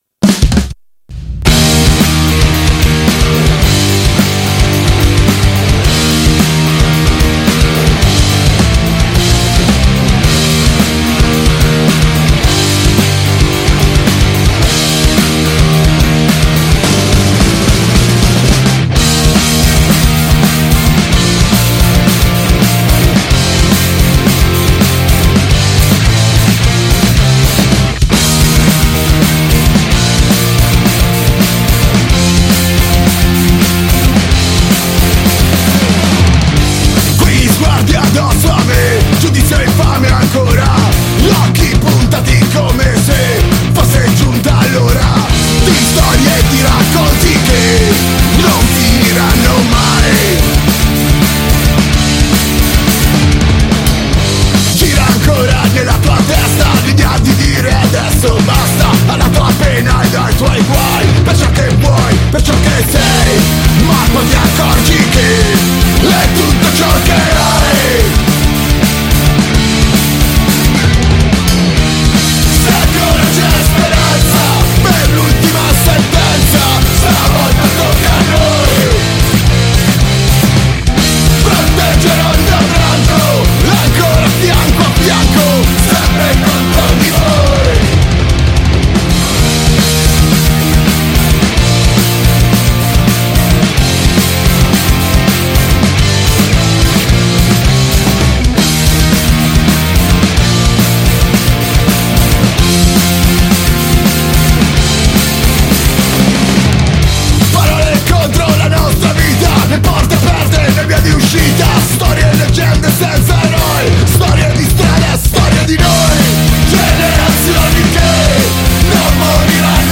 Intervista agli Azione Diretta | 20-2-23 | Radio Città Aperta